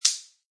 plasticplastic2.mp3